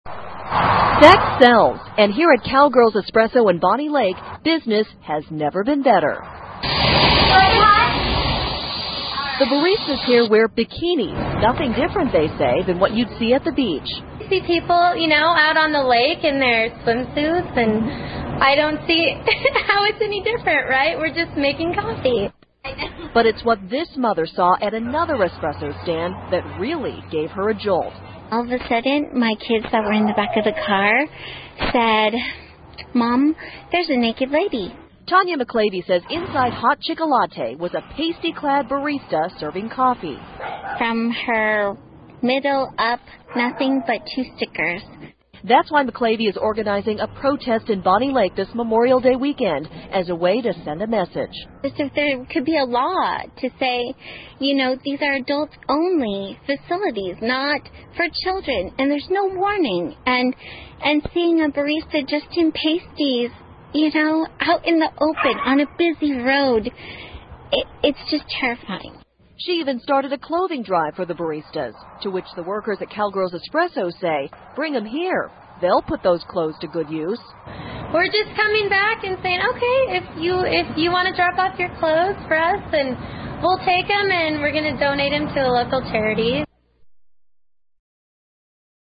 访谈录 2008-05-23&05-24 比基尼咖啡 听力文件下载—在线英语听力室